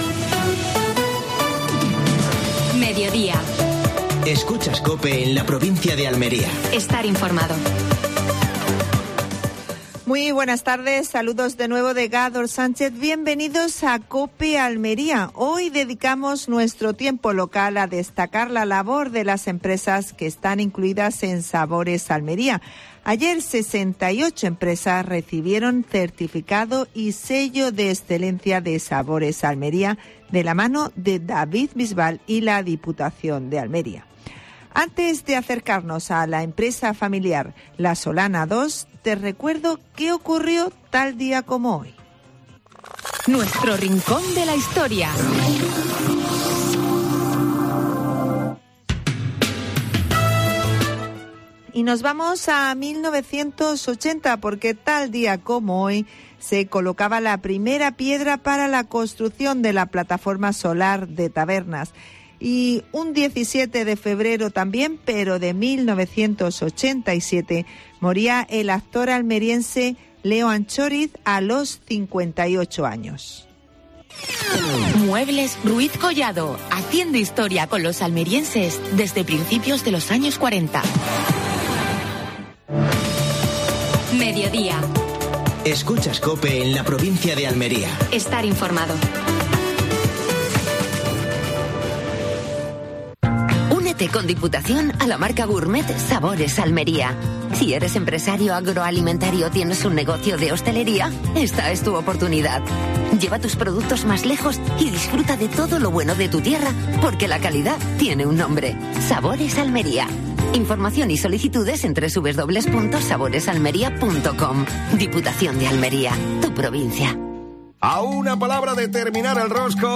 AUDIO: Actualidad en Almería. Entrevista